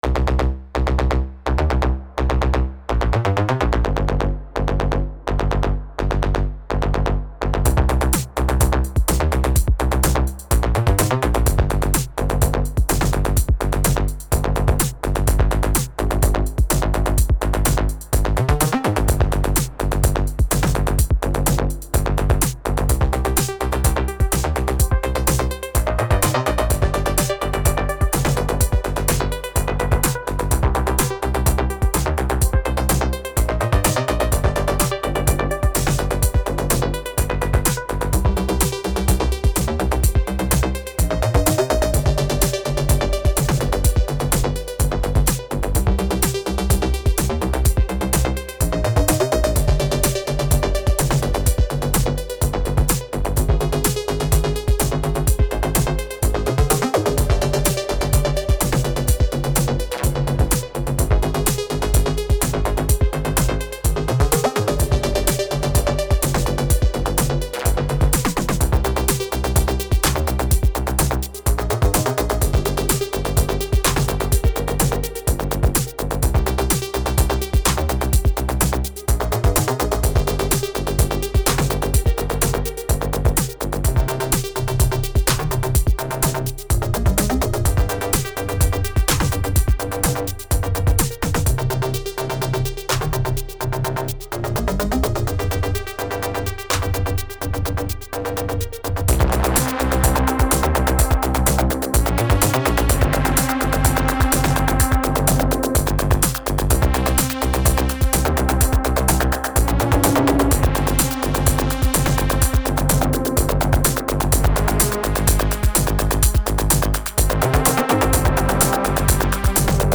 Three sketches.
I sacrificed an LFO on the bass track to get an exponential envelope for volume.
Straight from the Syntakt. Something’s wrong with my audio interface, apologies for the occasional boiling in the right channel.